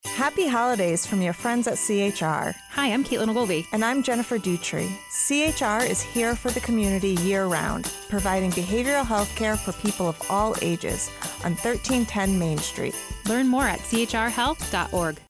With help from our friends at WILI-AM and I-98.3 FM, CHR joined local businesses to wish everyone in the Willimantic area happy and healthy holidays!